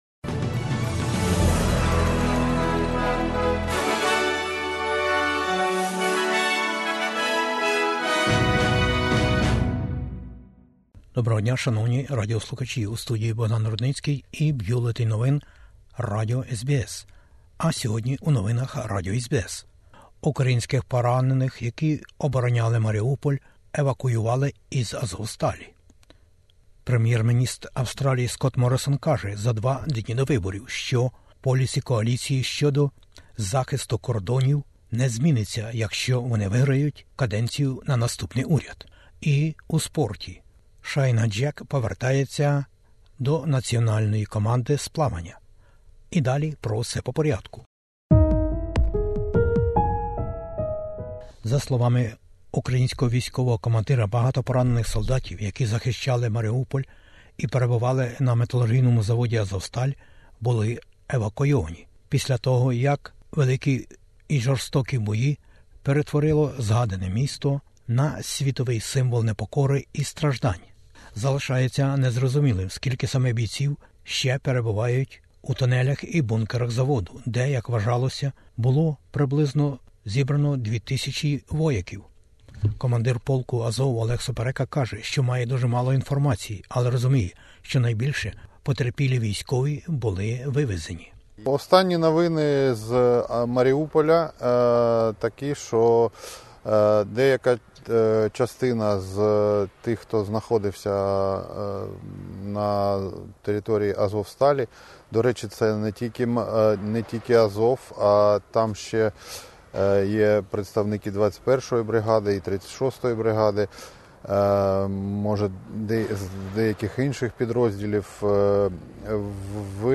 Бюлетень SBS новин українською мовою. Федеральні вибори - 21-го травня: останні плани, обіцянки, протистояння за 2 дні до головного дня вибору і симпатійдо лідерів та партій.